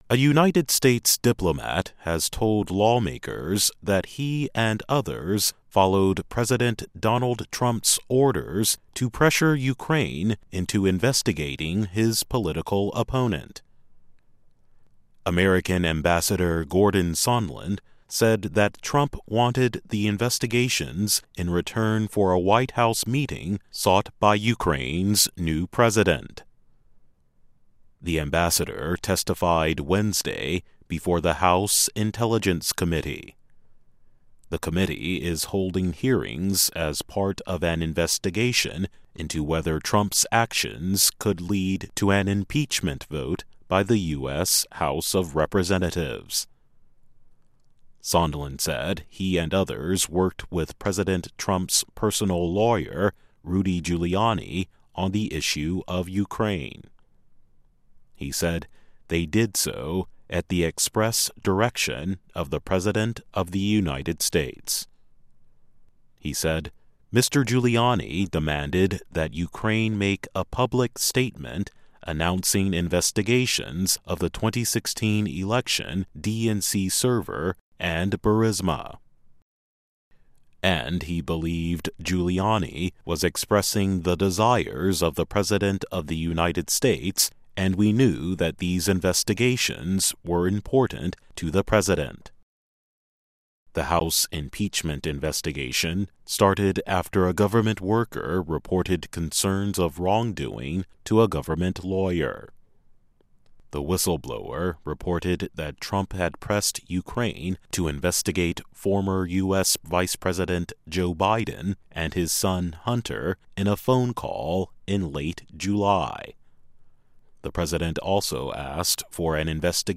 News
慢速英语:弹劾证人称特朗普下令对乌克兰施压